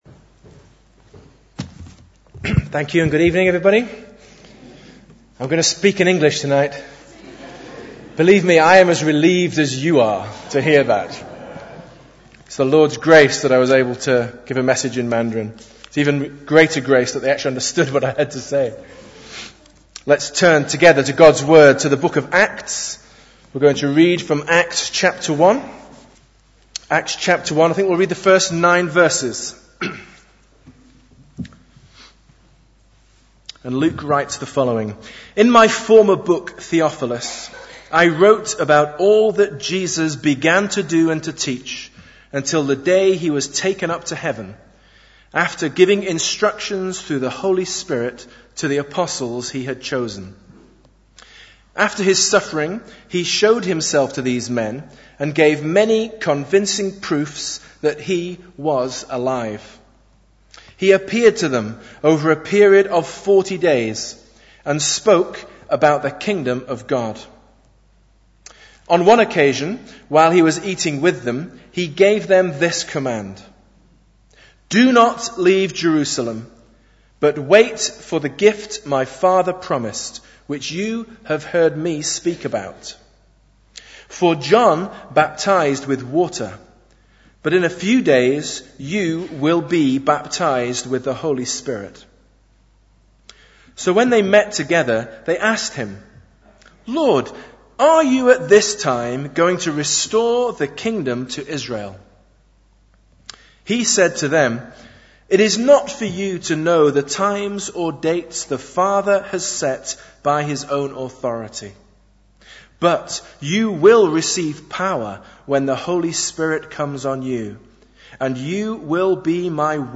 Bible Text: Acts 1:1-9 | Preacher: Visiting Speaker (OMF) | Series: Celebrating 150 years of CIM/OMF